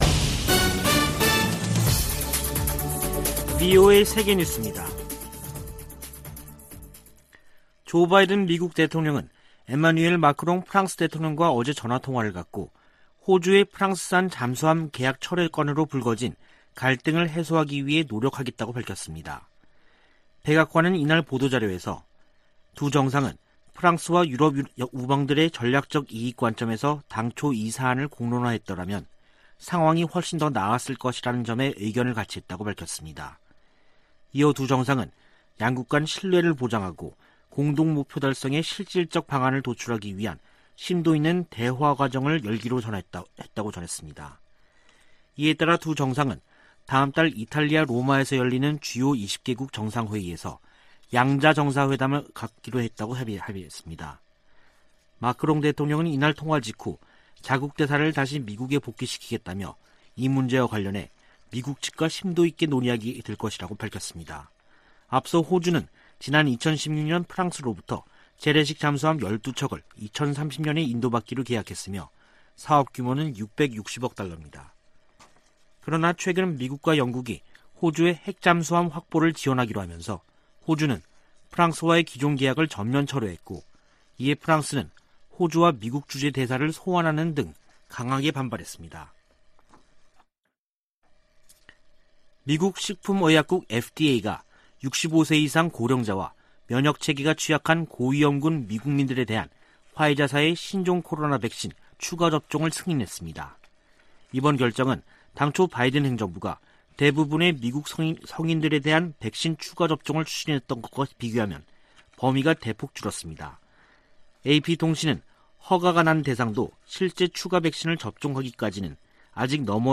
VOA 한국어 간판 뉴스 프로그램 '뉴스 투데이', 2021년 9월 23일 2부 방송입니다. 미국은 한국전쟁 종전선언 논의에 열려 있다고 미 국방부 대변인이 밝혔습니다. 미 상원 군사위원회가 2022 회계연도 국방수권법안 전문을 공개했습니다. '북한자유연합(NKFC)'이 24일 탈북 난민 구출의 날을 앞두고 시진핑 중국 국가주석에게 보내는 공개서한을 발표했습니다.